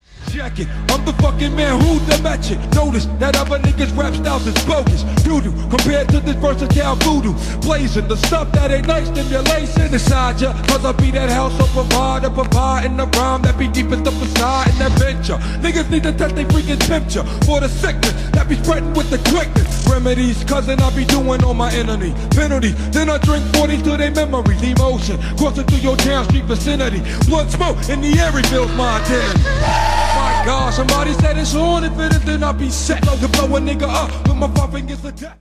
gangsta rap
хип-хоп